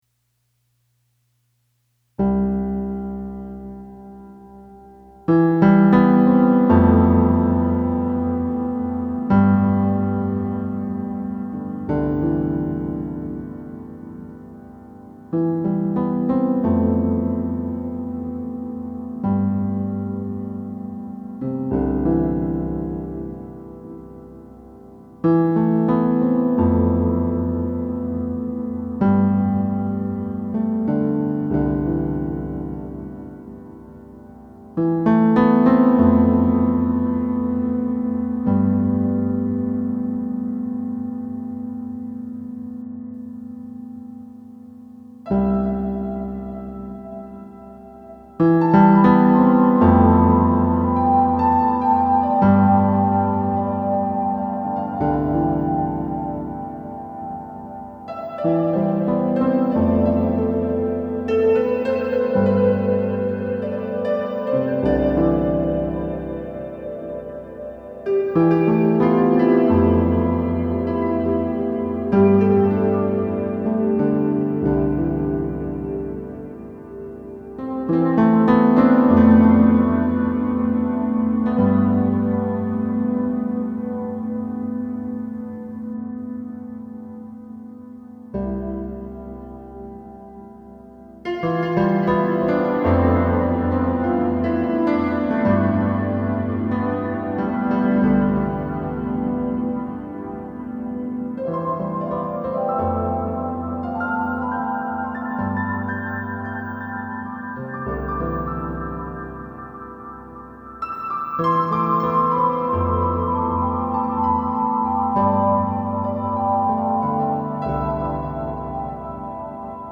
Lastly, I wrote and recorded a couple of short and simple instrumental songs on the piano shortly after he went Home.